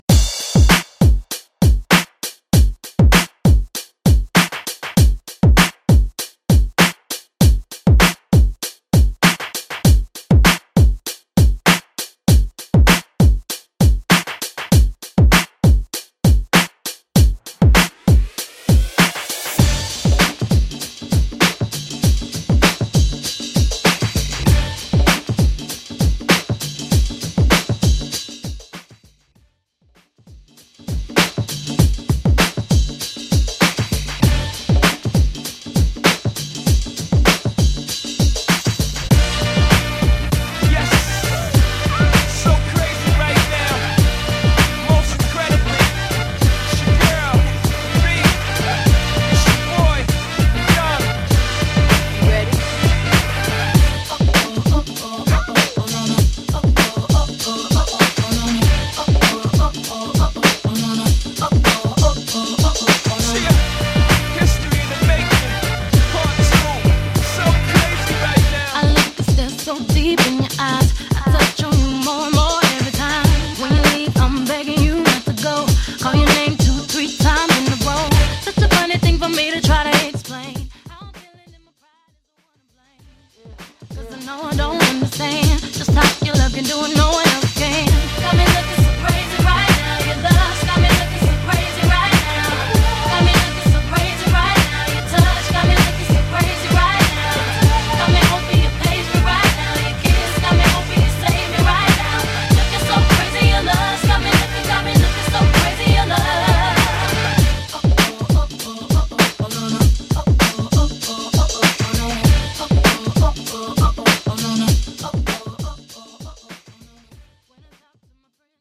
HIPHOP
R & B